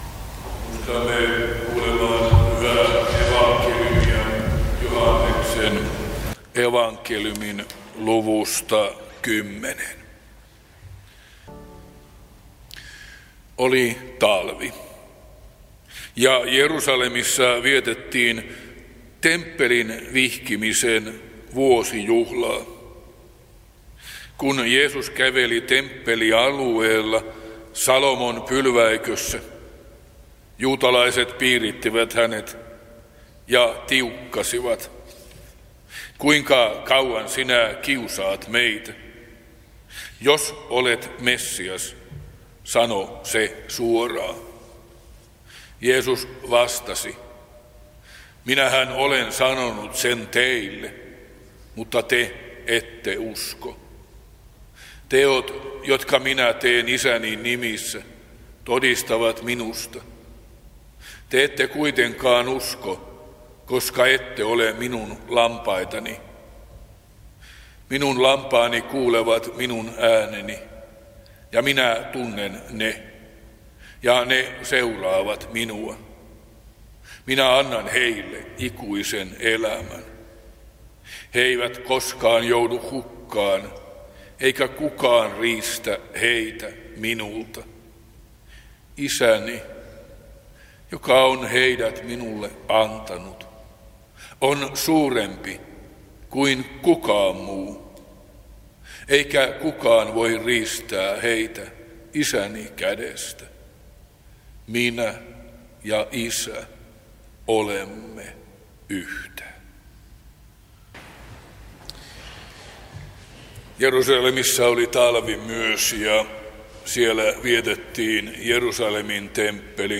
Teuva